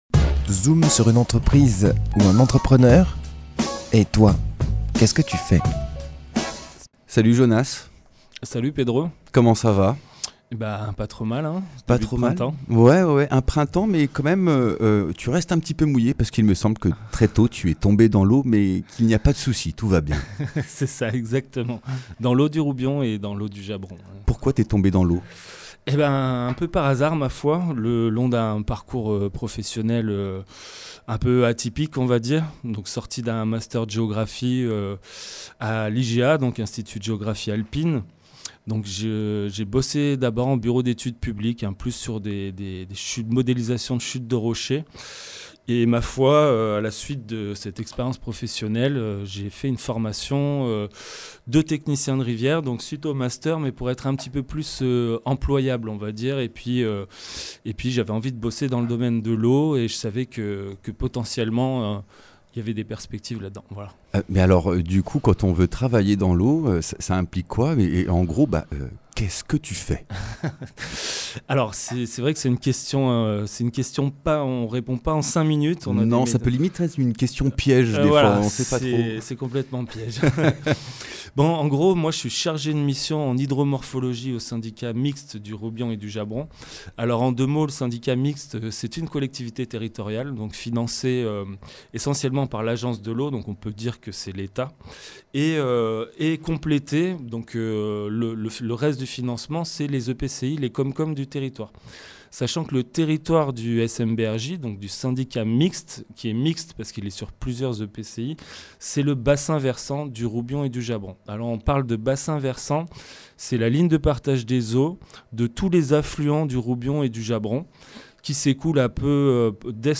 Dans le cadre du volet communication du contrat de rivière, le SMBRJ participe à des émissions de radio avec des acteurs locaux (Radio Saint Férréol, Radio